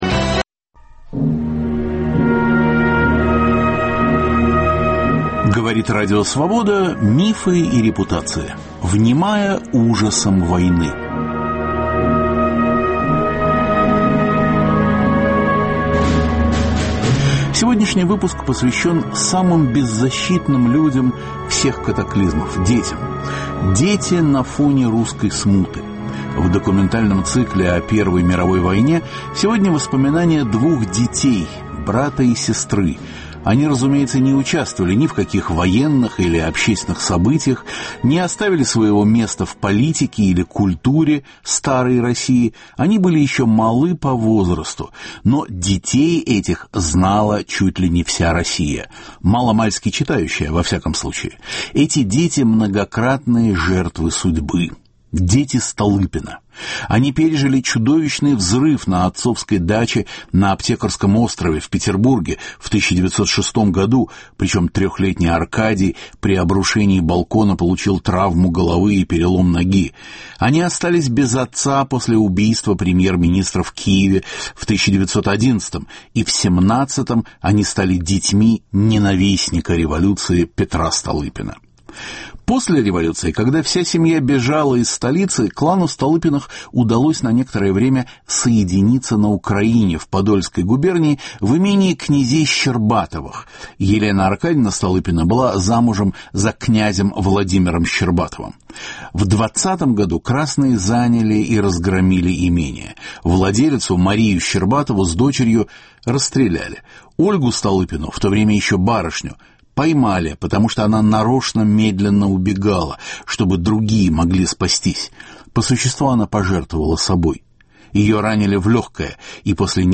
Вспоминают сын и дочь Петра Столыпина. Теракт на даче отца (1906), убийство в городском театре Киева (1911), революция и нападение отряда красных (1920). Записи бесед 1964 года.